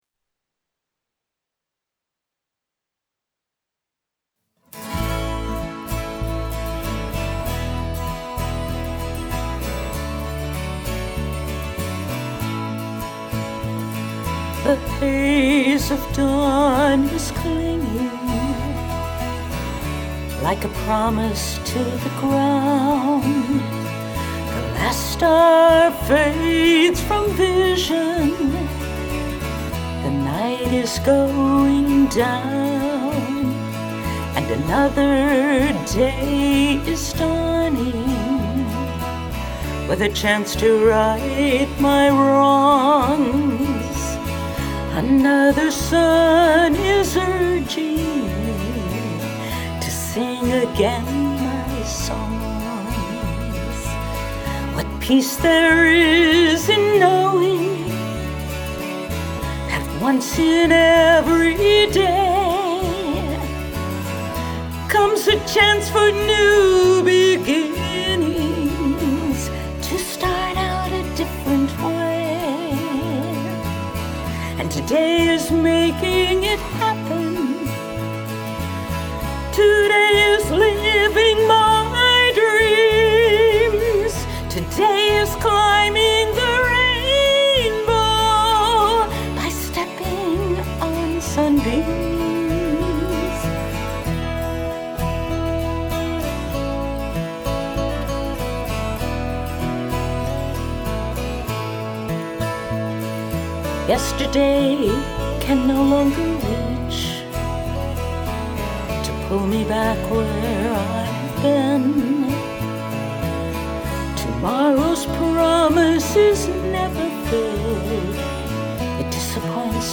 6-12 and tenor guitars